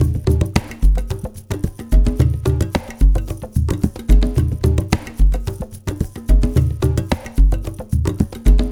APH ETHNO1-R.wav